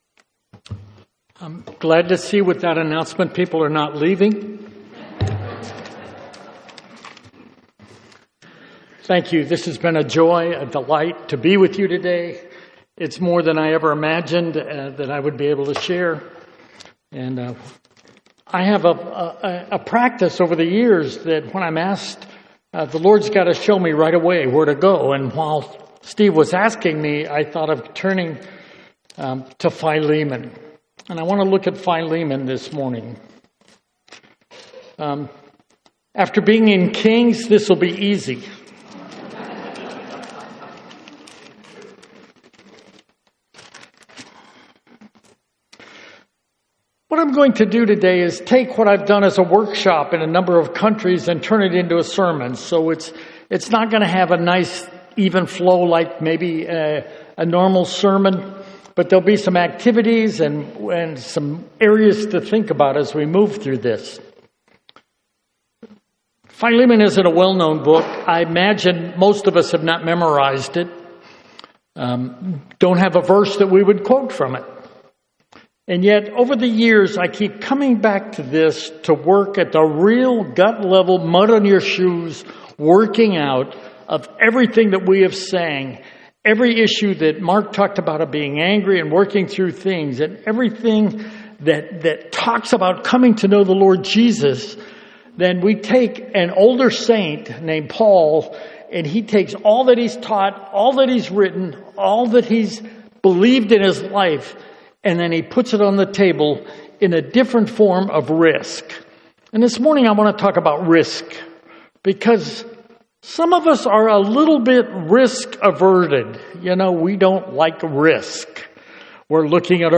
Sermons on Philemon 1:1-25 — Audio Sermons — Brick Lane Community Church